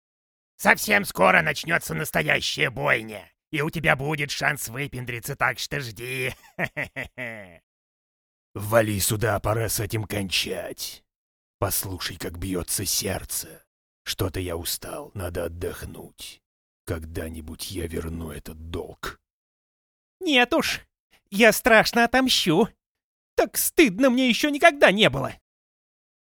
My voice is a clean, accent-free native Russian, adaptable across a wide tonal range – from formal and authoritative to engaging and friendly.
My setup includes an Oktava MKL-5000 tube microphone which gives a rich, warm sound to my voice, ideal for narration, commercials, and corporate reads.
Multi-Character Demo